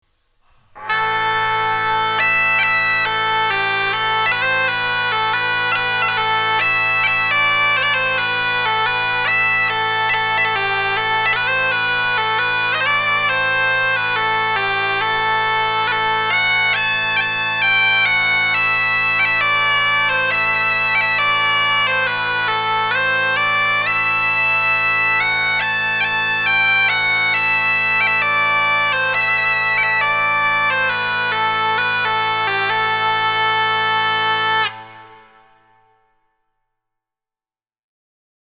„Großer“ Mittelalter Dudelsack in a-moll:
Der Klang ist Aufgrund der konischen Bohrung in der Spielpfeife und der großen Schalltrichter sehr laut.
Tonumfang: g´-a´´
Tonart: A-dorisch und a-moll
Bordun: 1-3 erhältliche Bordune:
Klangbeispiel